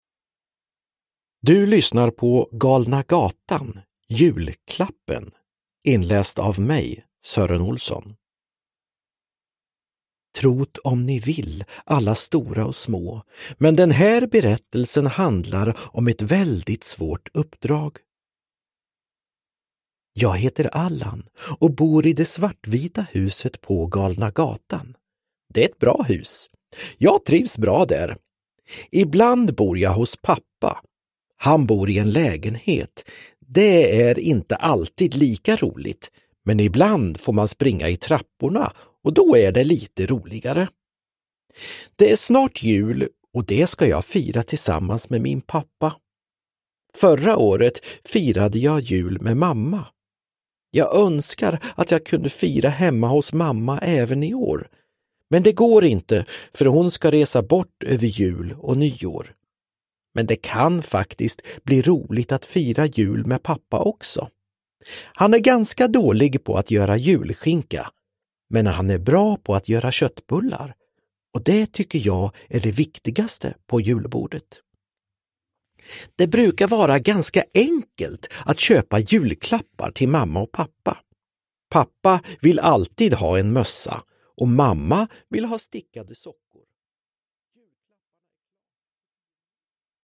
Uppläsare: Sören Olsson, Anders Jacobsson